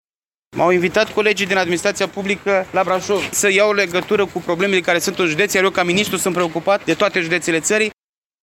Ministrul mediului s-a întâlnit, astăzi, la Primăria Braşov cu autorităţile locale şi judeţene, cu operatorii de salubrizare şi cu reprezentanţii Facultăţii de Silvicultură şi ai Regiei Locale a Pădurilor Kronstadt.